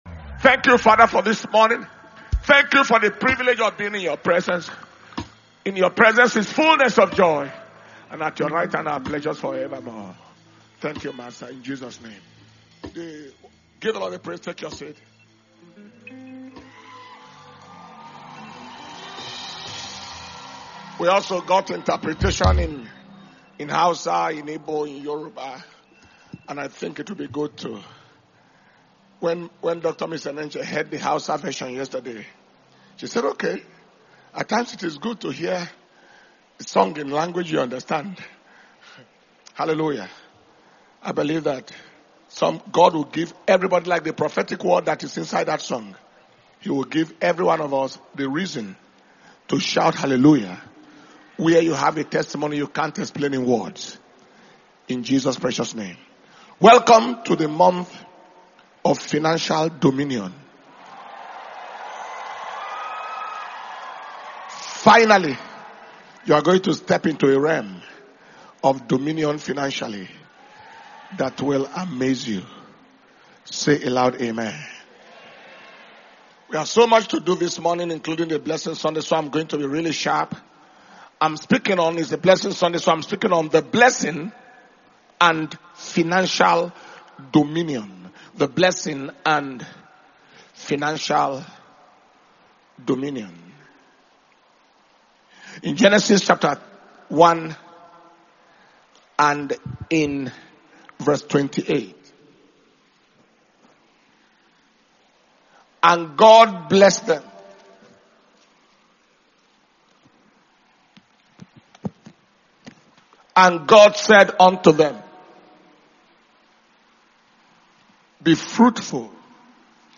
March 2025 Blessing Sunday Service- Sunday 2nd March 2025